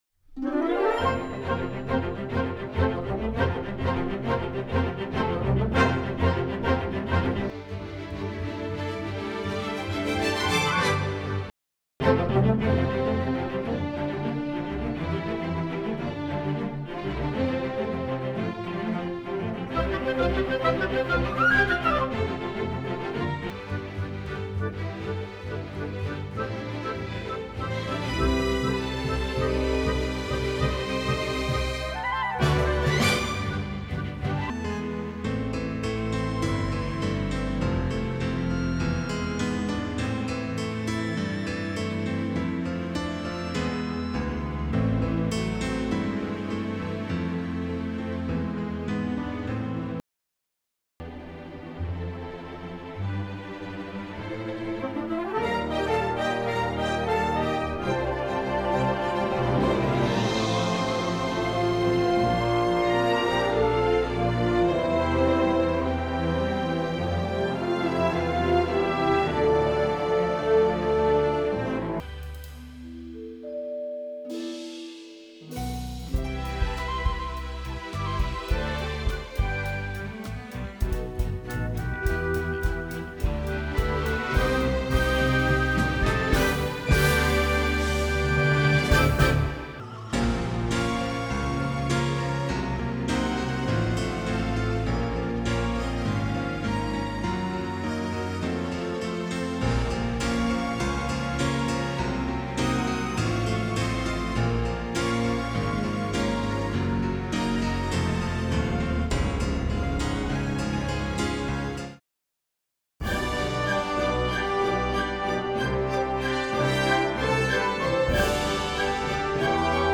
Сделал face to face comparisson диснеевского саундтрека, которым вдохновлялся.